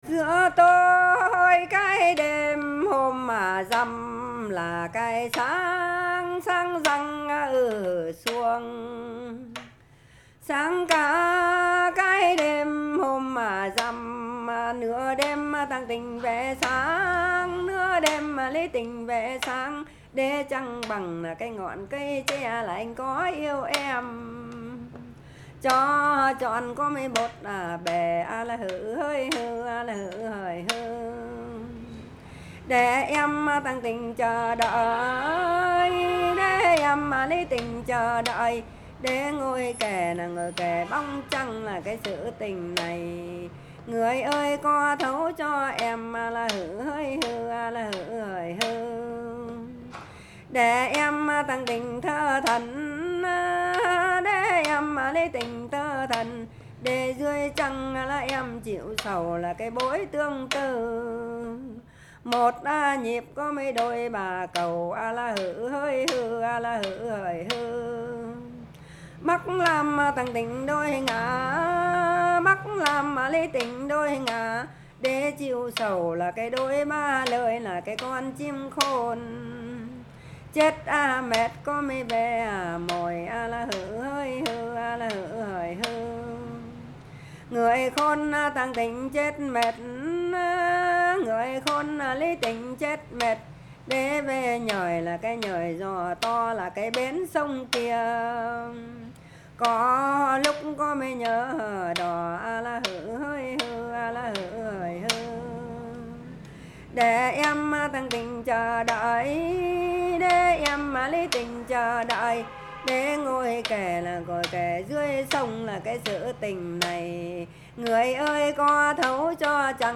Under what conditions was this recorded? I recorded the songs in 2004, in the city of Bac Ninh, just outside Hanoi.